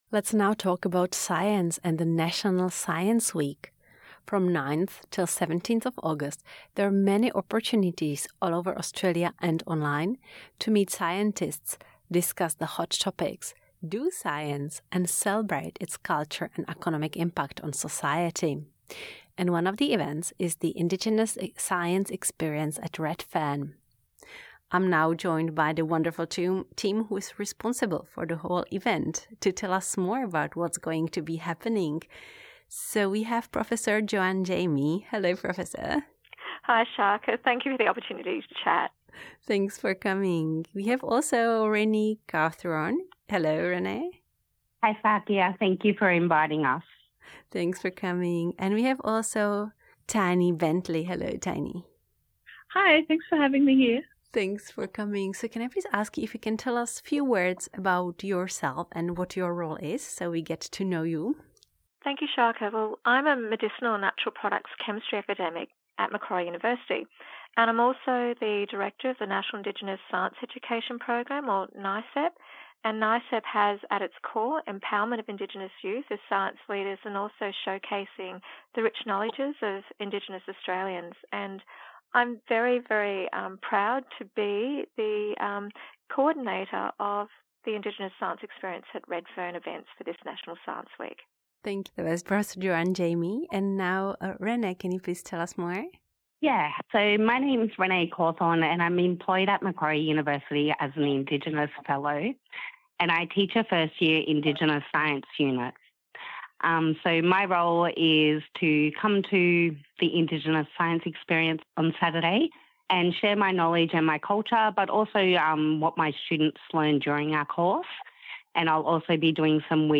NITV Radio spoke to the organisers of the Indigenous Science Experience at Redfern